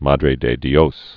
(mädrā dā dē-ōs, mädrĕ dĕ dyōs)